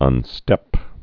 (ŭn-stĕp)